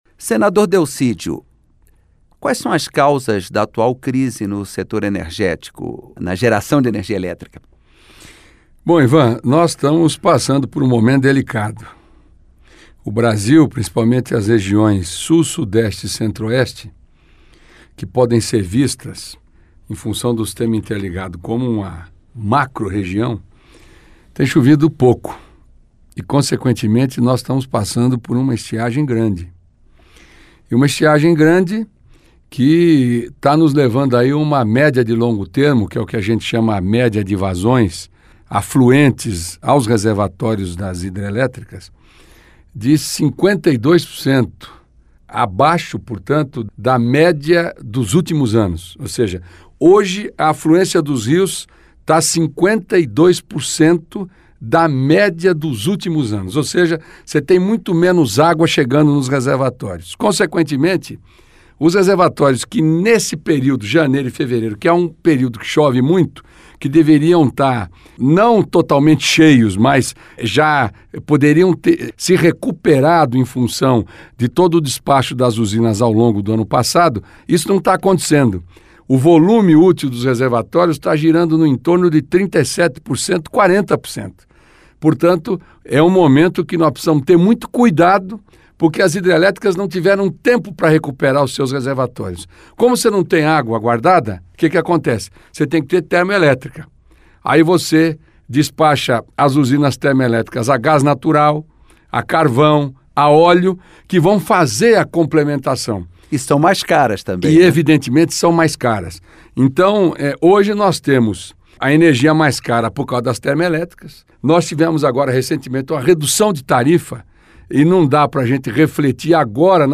Entrevista com o senador Delcídio do Amaral (PT-MS).